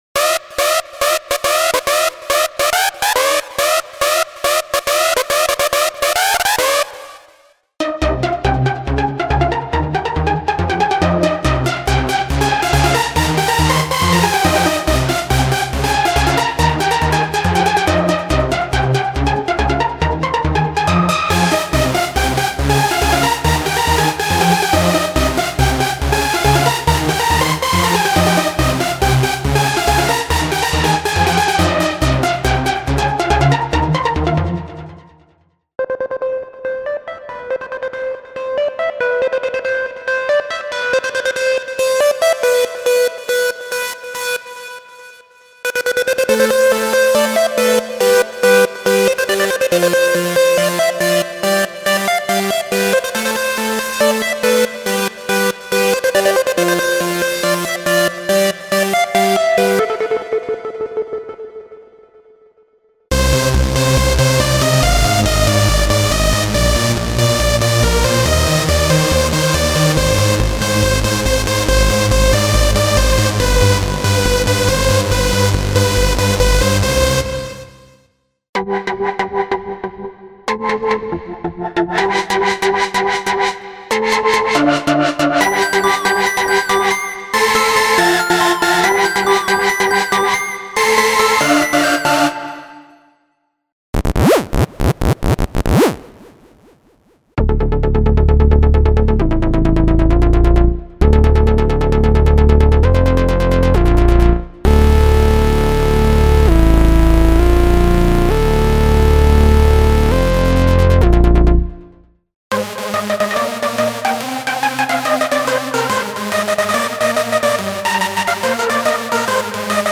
Rocketjump Hardstyle Universe For Access Virus TI (!!!! OS 4.5.3.00 !!!!)